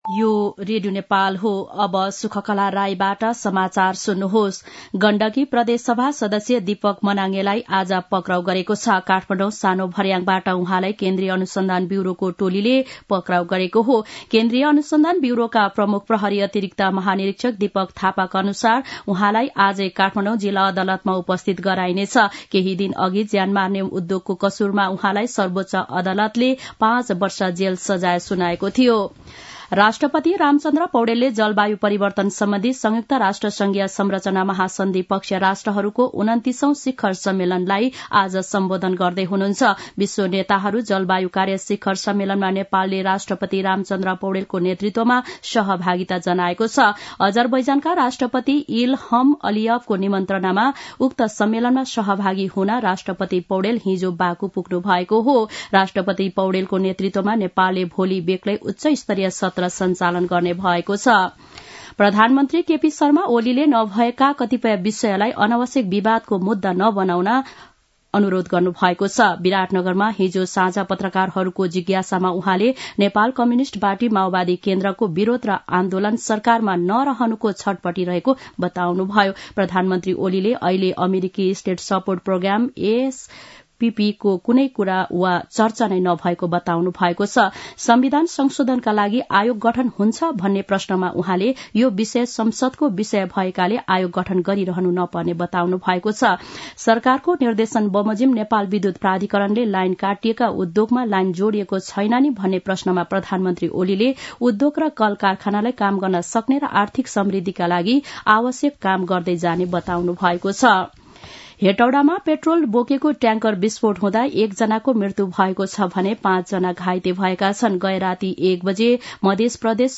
दिउँसो १ बजेको नेपाली समाचार : २८ कार्तिक , २०८१
1-pm-news-1-2.mp3